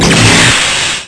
1 channel
Ffbounce.wav